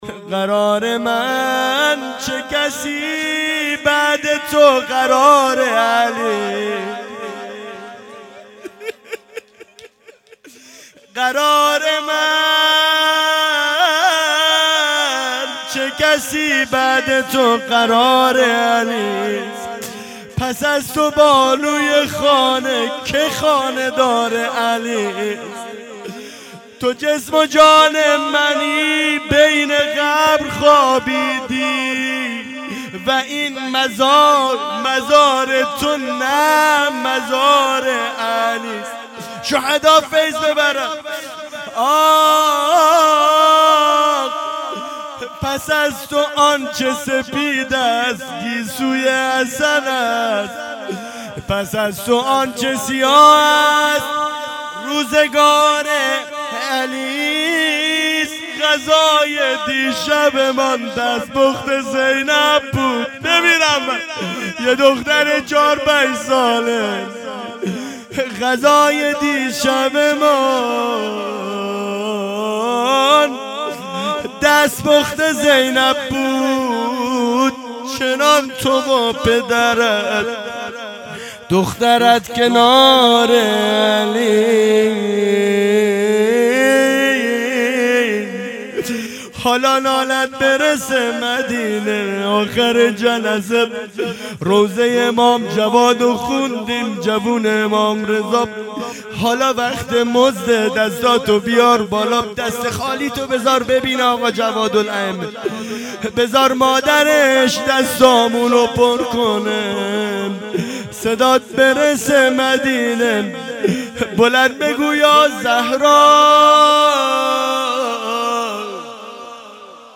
شهادت آقا جوادالائمه 1402